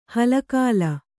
♪ hala kāla